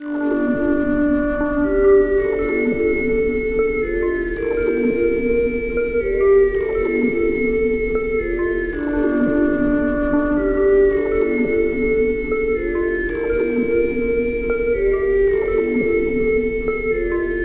描述：类型：Pad/合成器 调性：G小调 BPM：110
Tag: 110 bpm Trap Loops Pad Loops 2.94 MB wav Key : G